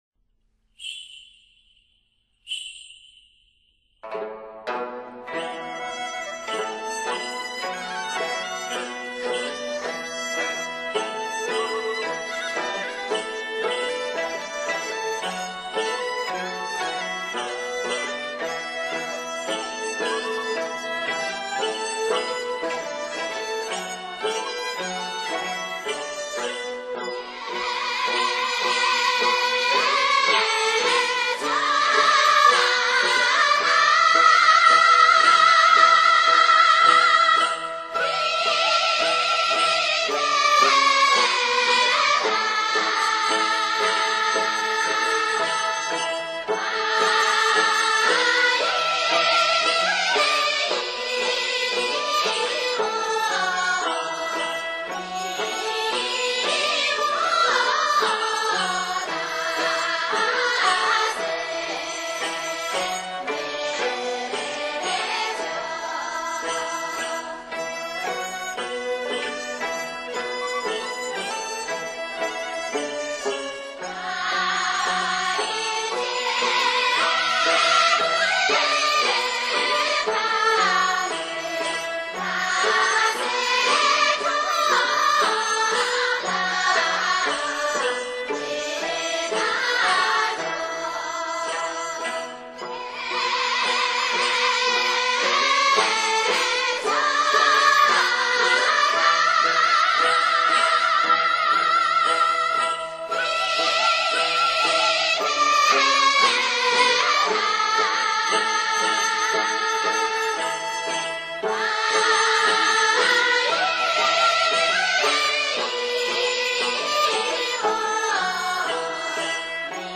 音乐类型:民族音乐